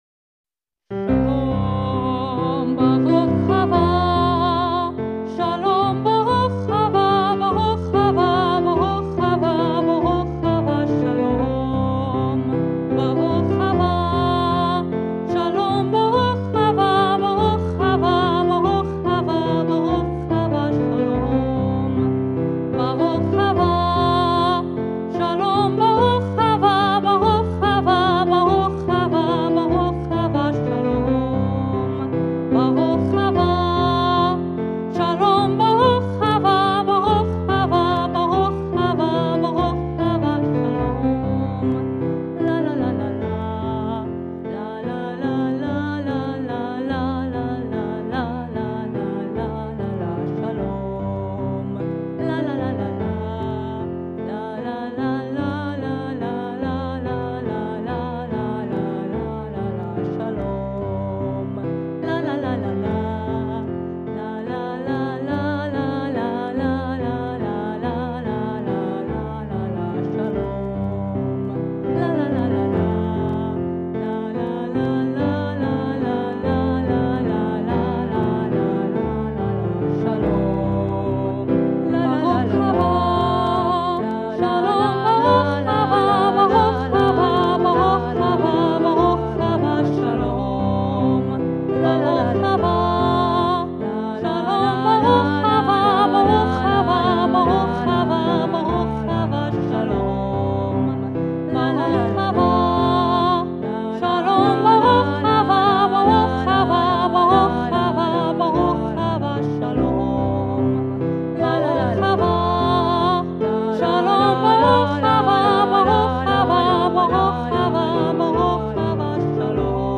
Original Songs
Not every song I wrote on piano was in 3/4 or waltz time, even though all of these are. 🙂